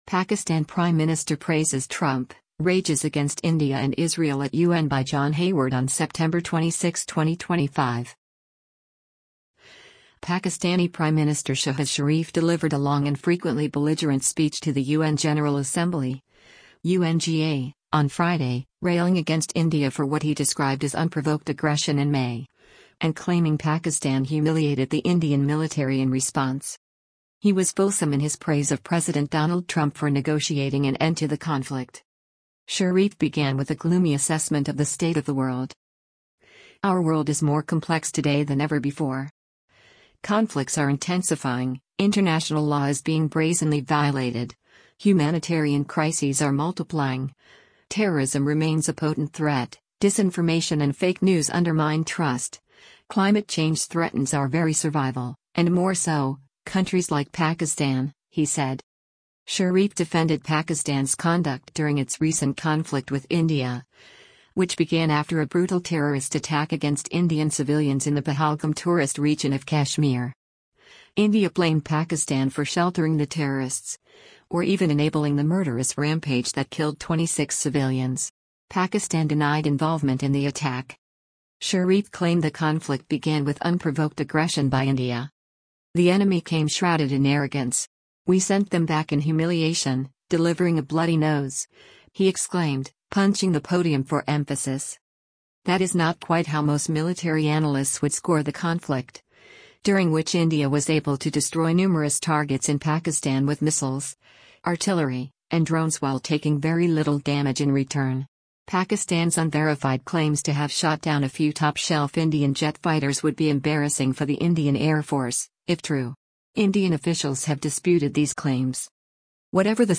Pakistani Prime Minister Shehbaz Sharif delivered a long and frequently belligerent speech to the U.N. General Assembly (UNGA) on Friday, railing against India for what he described as unprovoked aggression in May, and claiming Pakistan humiliated the Indian military in response.
“The enemy came shrouded in arrogance. We sent them back in humiliation, delivering a bloody nose!” he exclaimed, punching the podium for emphasis.
Some of the Pakistani delegates to the United Nations burst into applause and chanting after he made the claim.